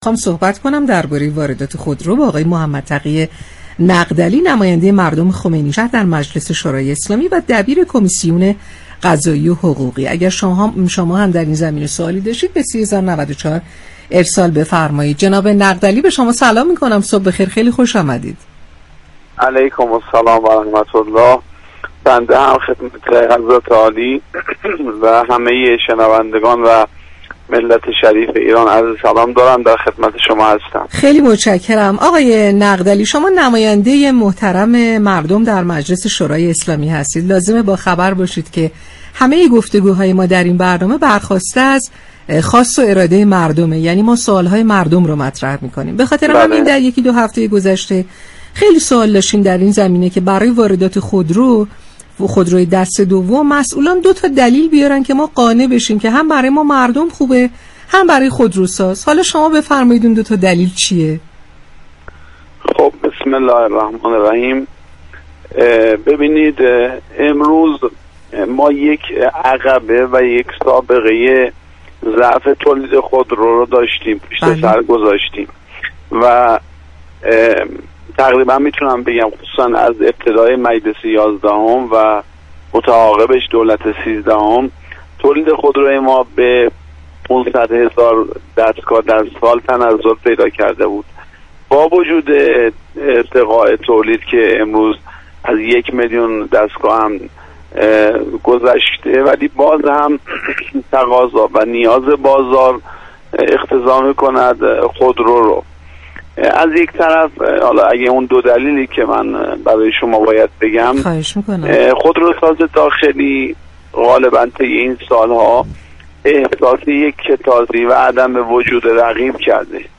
به گزارش پایگاه اطلاع رسانی رادیو تهران، محمدتقی نقدعلی دبیر دوم كمیسیون قضایی و حقوقی مجلس شورای اسلامی در گفت و گو با «شهر آفتاب» موافق طرح واردات خودرو اظهار داشت: تولید خودرو از ابتدای مجلس یازدهم و دولت سیزدهم به 500 هزار دستگاه در سال تنزل پیدا كرد.